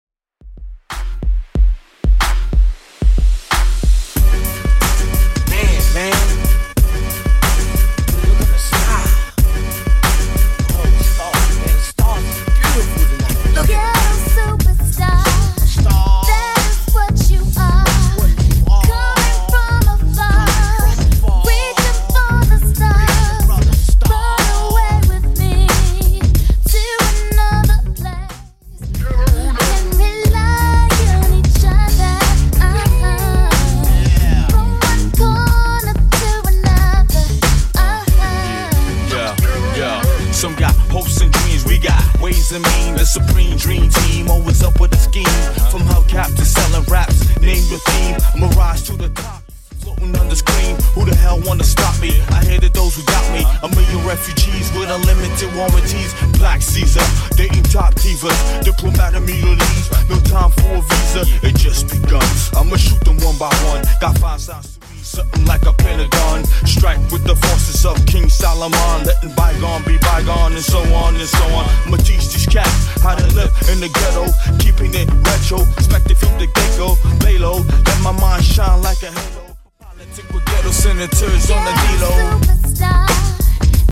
BPM: 92 Time